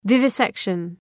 Προφορά
{,vıvı’sekʃən} (Ουσιαστικό) ● ζωοτομία